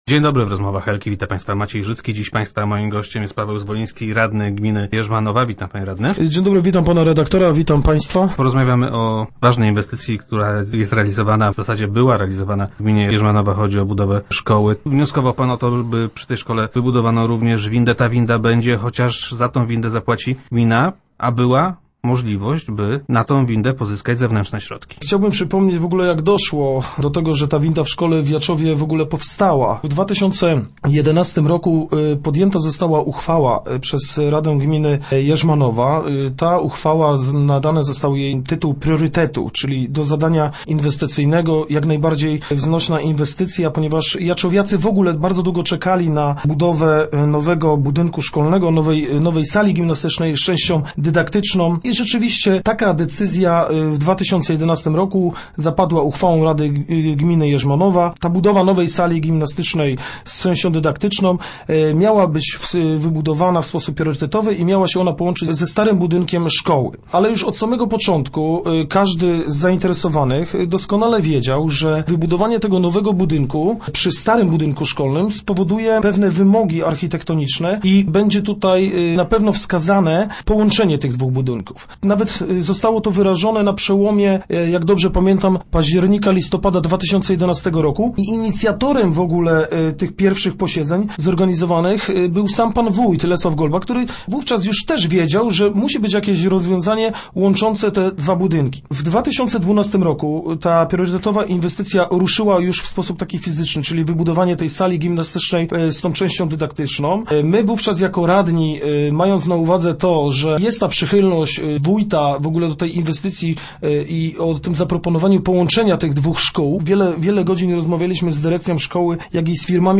Inwestycję gotów był wesprzeć Państwowy Fundusz Rehabilitacji Osób Niepełnosprawnych. - Władze gminy zbyt późno rozpoczęły starania o tę dotację – twierdzi Paweł Zwoliński, radny gminny, który był gościem Rozmów Elki.